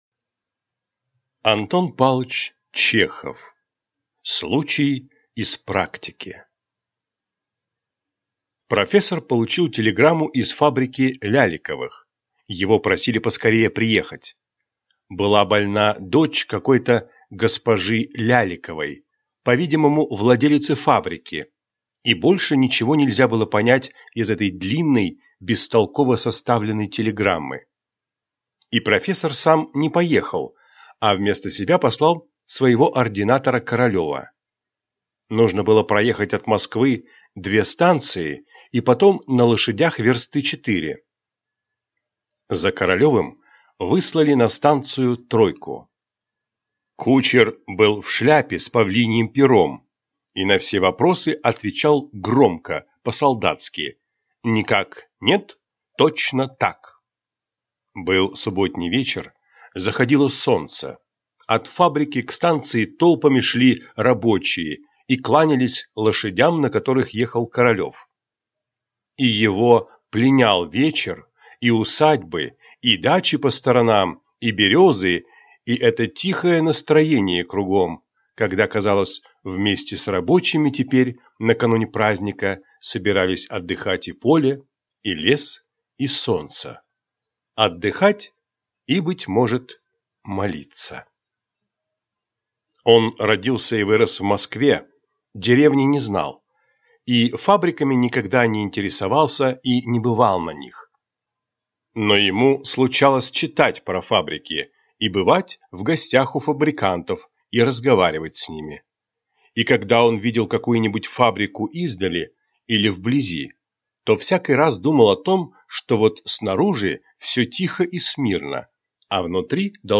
Aудиокнига Случай из практики Автор Антон Чехов Читает аудиокнигу